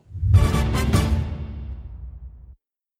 PLAY Corneta PDT 2021